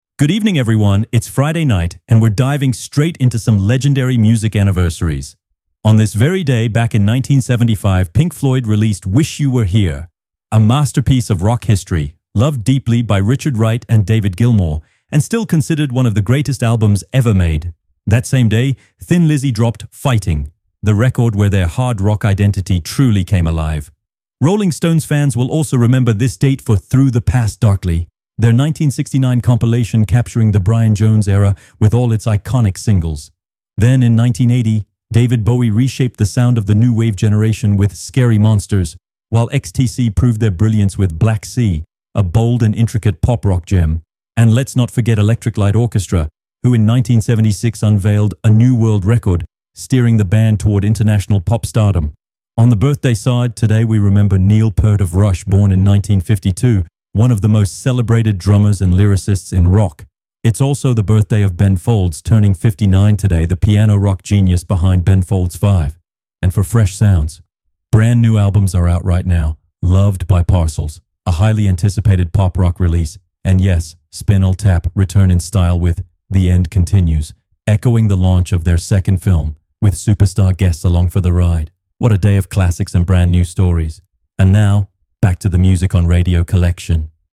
You're listening to the Pop Rock column on Radio Collection, the free, ad-free web radio station that broadcasts the greatest classics and new releases in Hi-Fi quality.